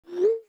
Звук отправки сообщения в iMessage